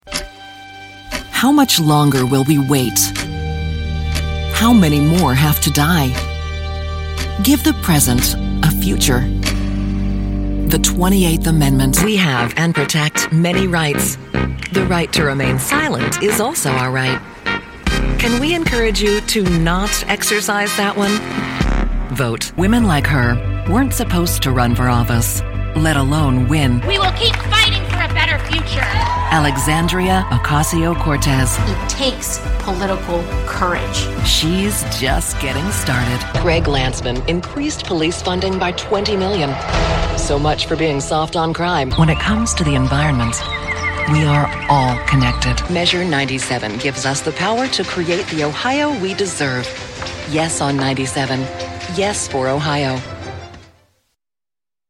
Female Voice Over Actor
Political Democratic